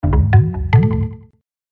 SMS_lub_MMS_3.mp3